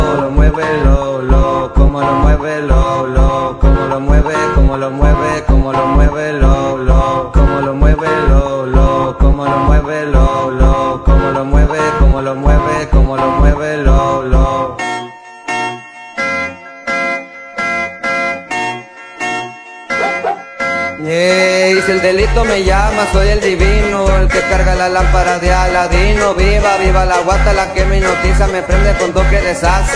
Жанр: Альтернатива / Латино
Urbano latino